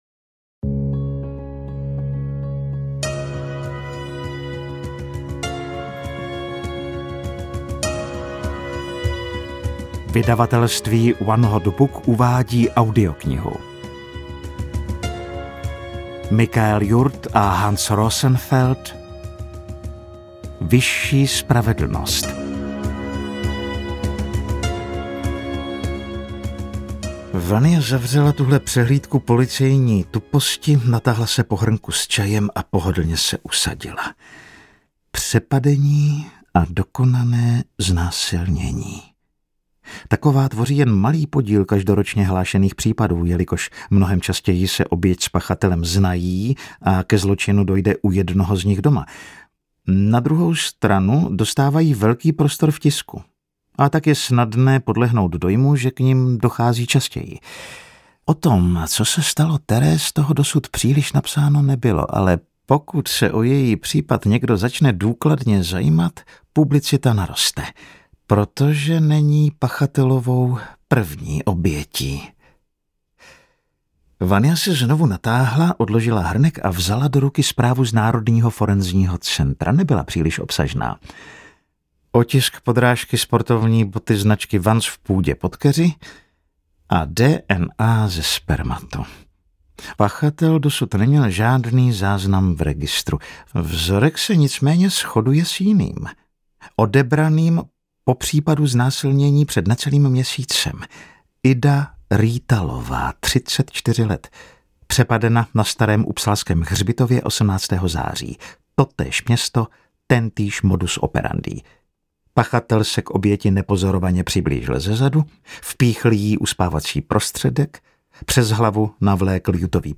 Hörbuch
Audiobuch zum Download